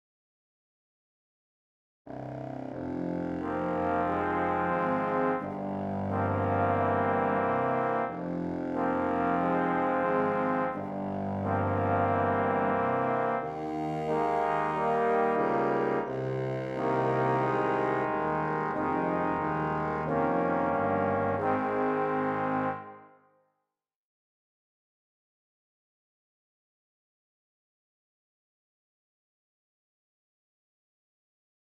Un mélange des timbres les plus graves de l’orchestre (contrebasses, violoncelles, contrebasson et basson) sur un motif pesant de marche lente, soutenu par des accords aux trombones. La musique est ici lourde de sens, inquiétante et caverneuse, dans la tonalité de fa mineur.